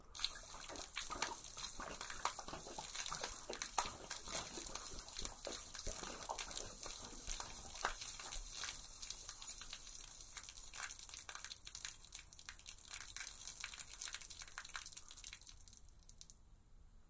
描述：轻度液体倾泻，安静，飞溅，水，涓涓细流，轻度咕噜声，咕噜声
Tag: 涓流 液体注入 溅水